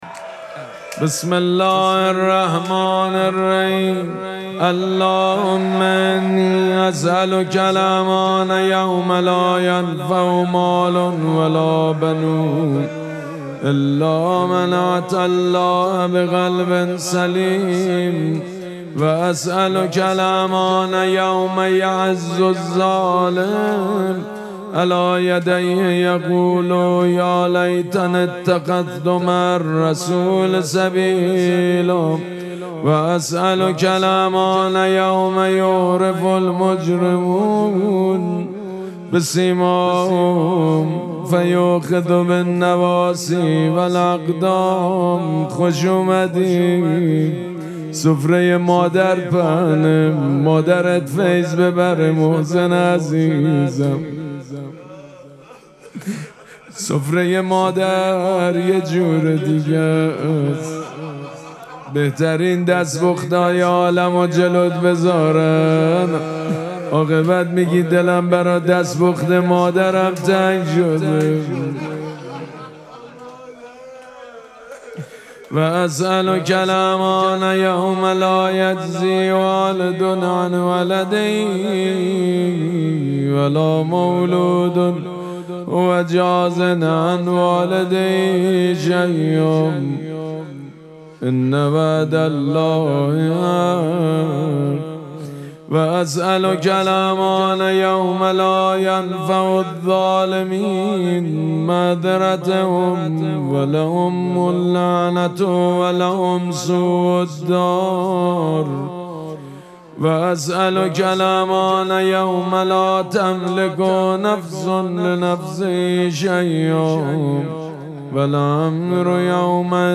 مراسم مناجات شب هفدهم ماه مبارک رمضان دوشنبه ۲۷ اسفند ماه ۱۴۰۳ | ۱۶ رمضان ۱۴۴۶ حسینیه ریحانه الحسین سلام الله علیها
مداح حاج سید مجید بنی فاطمه